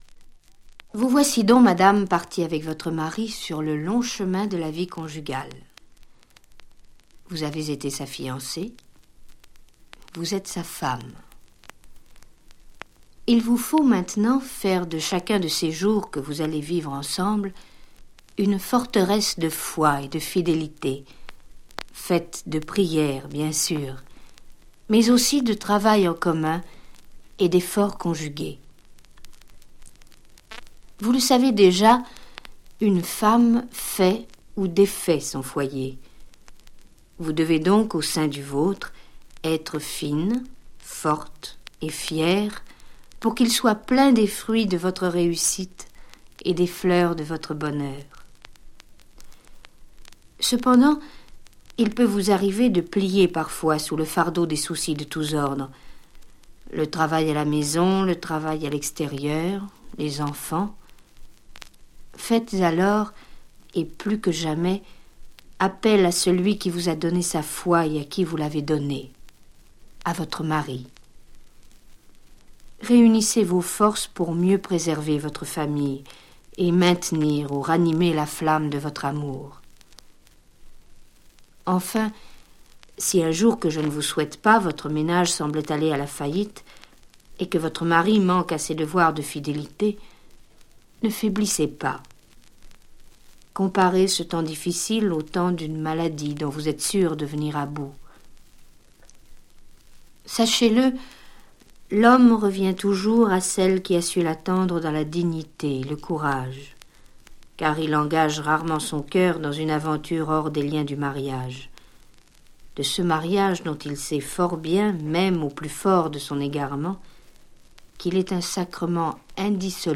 Le disque force l'attention, et cette "troisième voix" du dialogue, affectueuse et ferme, proche et distante aidera à faire les mises au point indispensables avec toute la délicatesse souhaitée.